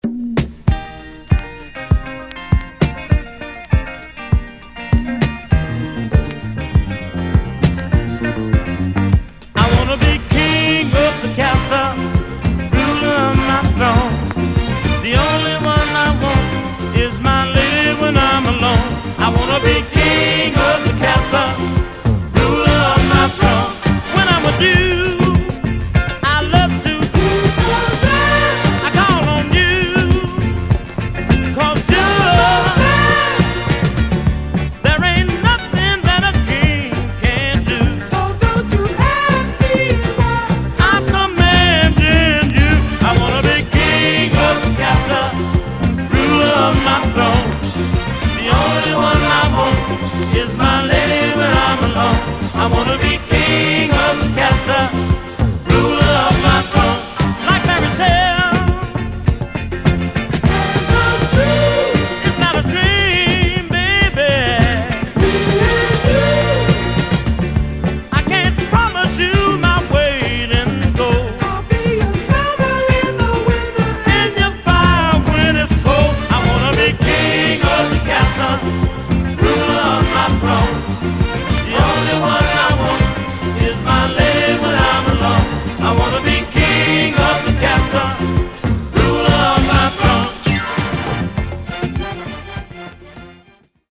Album未収録の爽やかPhilly Soul！
軽快なDisco Number